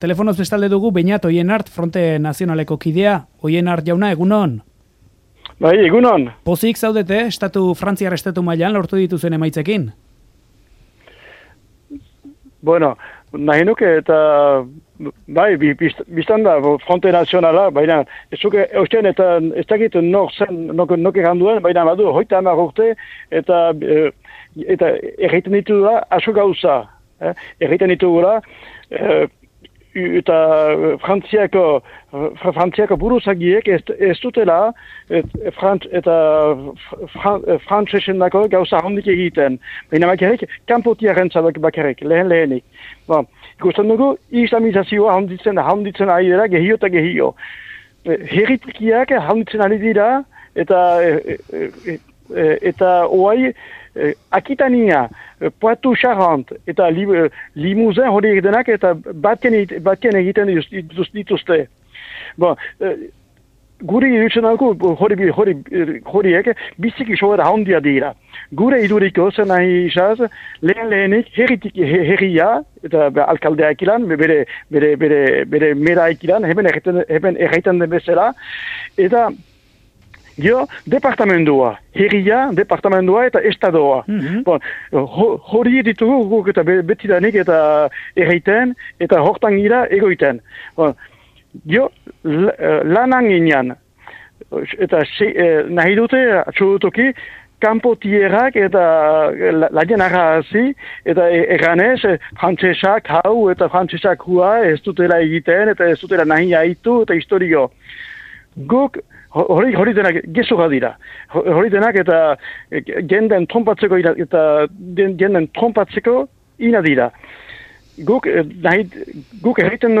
elkarrizketa